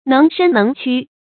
能伸能屈 注音： ㄣㄥˊ ㄕㄣ ㄣㄥˊ ㄑㄩ 讀音讀法： 意思解釋： 見「能屈能伸」。